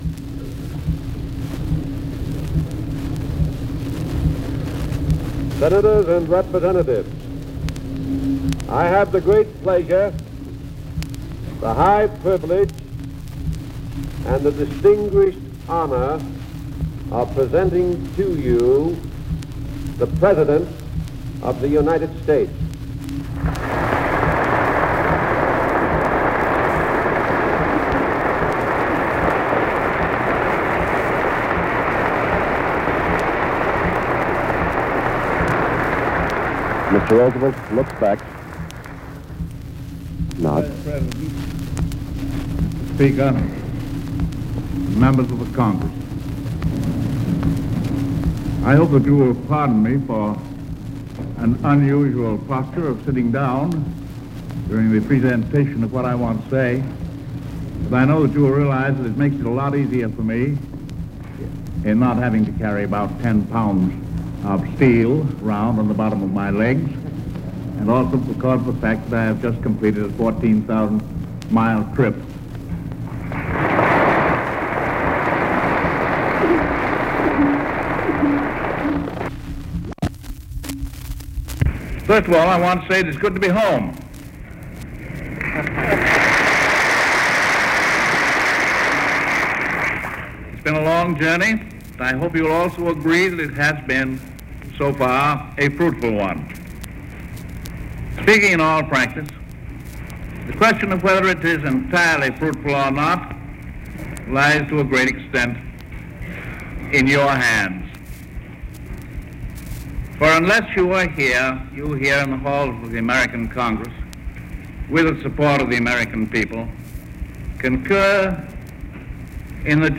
March 1, 1945: Address to Congress on Yalta | Miller Center